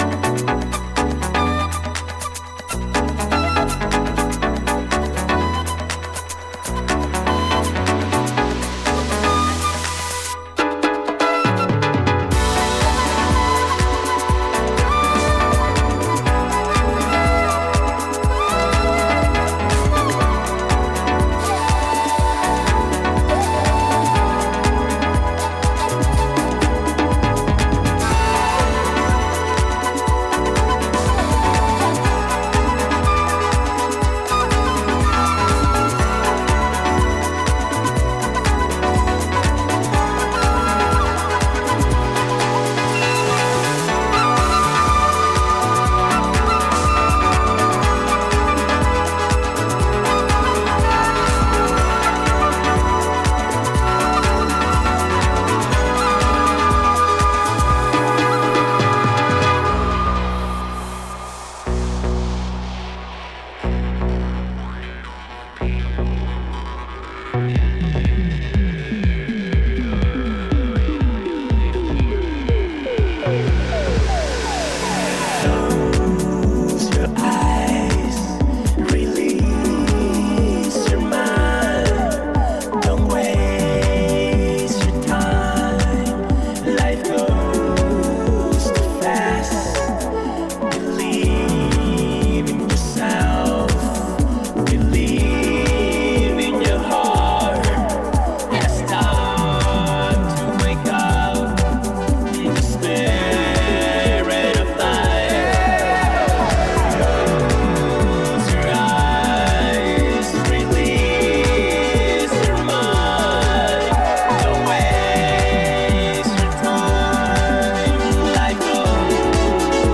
パーカッシヴなリズムに浮遊シンセやフルートなどを配しながらビルドアップしていく